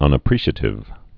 (ŭnə-prēshə-tĭv, -shē-ātĭv)